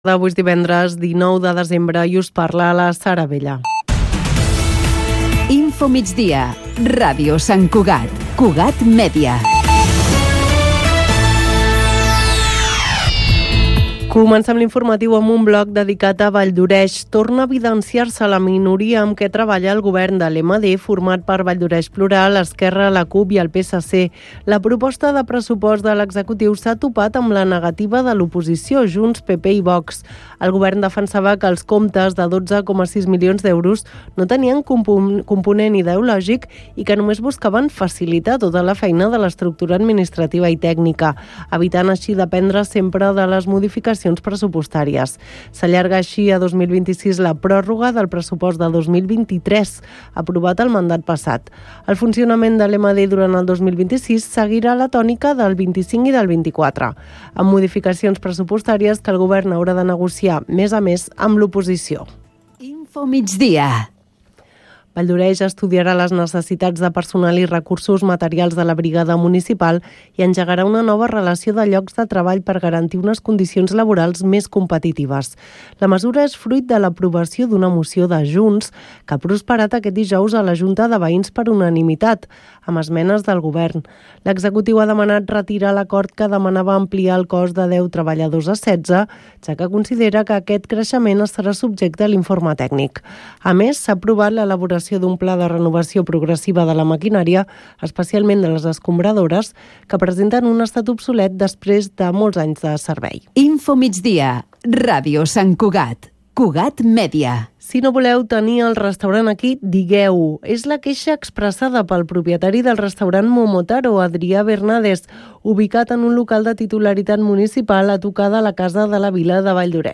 Obrim els micròfons als veïns i veïnes de Sant Cugat. Un espai d’opinió i debat